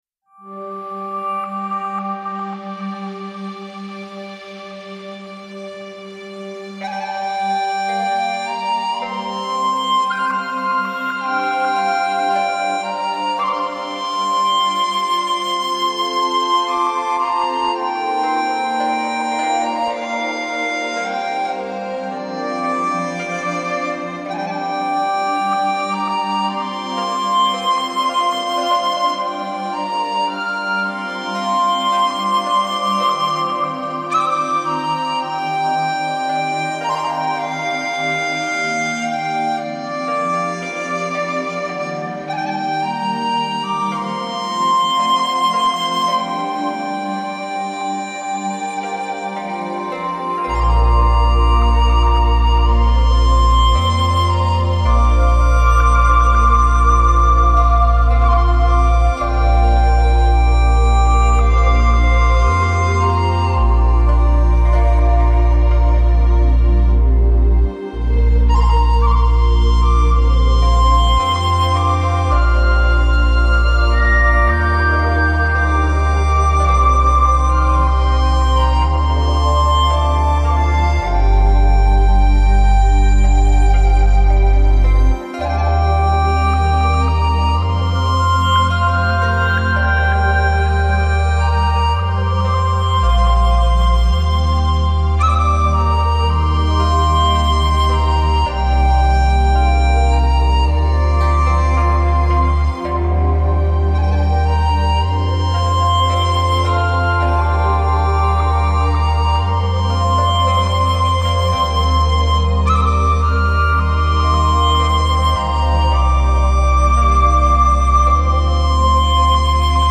音乐风格: New Age